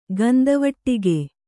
♪ gandavaṭṭige